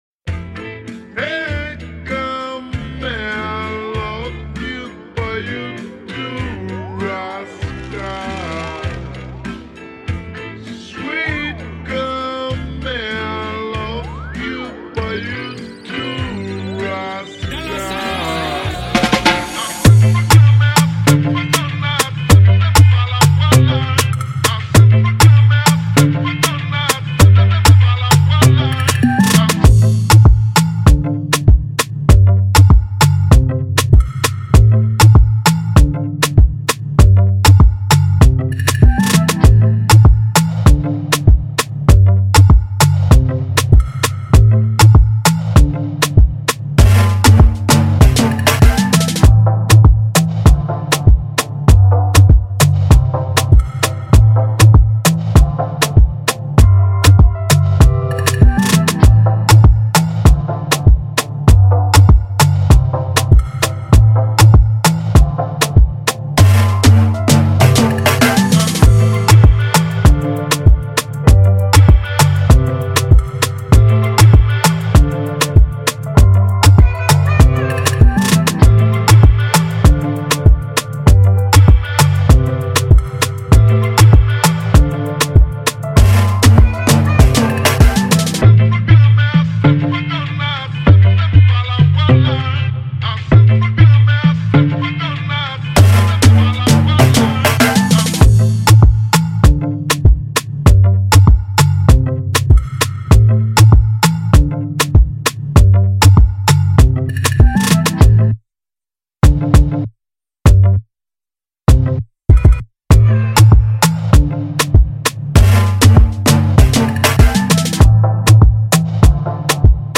Riddim Instrumental